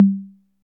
Index of /90_sSampleCDs/Roland L-CD701/DRM_Drum Machine/KIT_TR-808 Kit